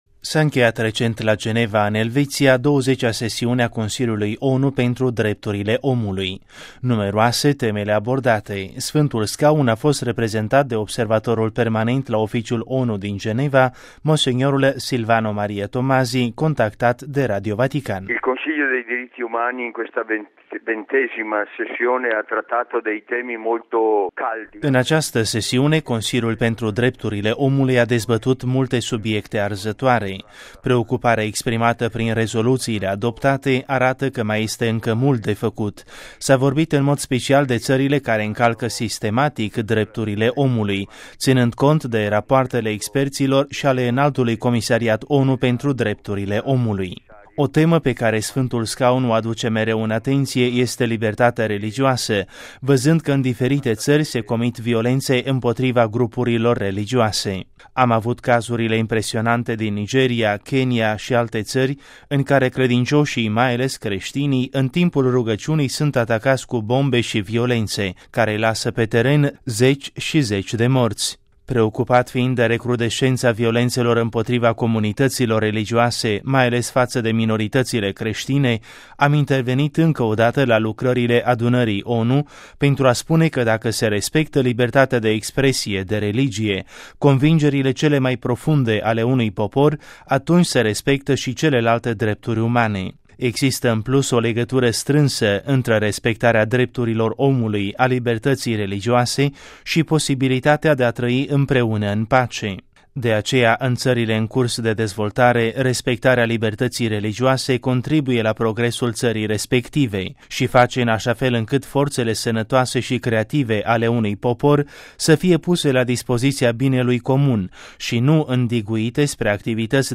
Sf. Scaun a fost reprezentat de observatorul permanent la Oficiul ONU din Geneva, mons. Silvano Maria Tomasi, contactat de Radio Vatican: